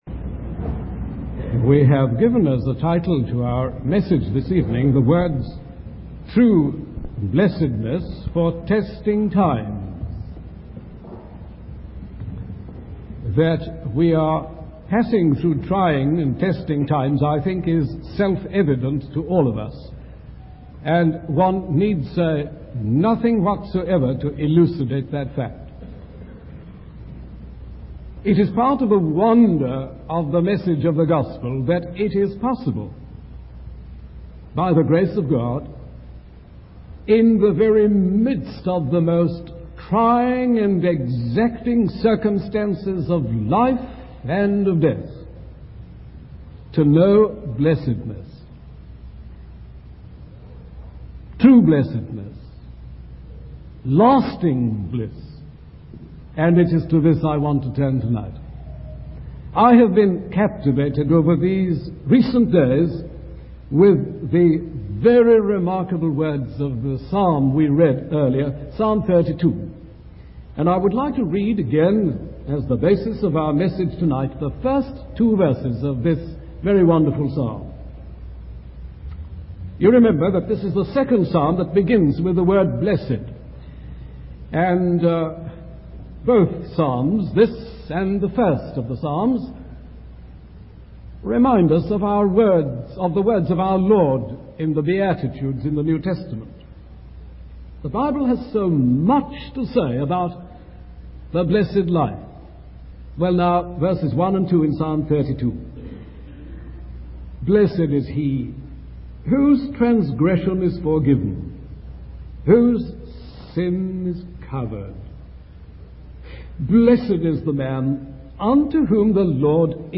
In this sermon, the speaker emphasizes the necessity of forgiveness due to the universal reality of human sin. The psalmist uses different angles to portray the hideousness of sin, highlighting its presence in every human heart.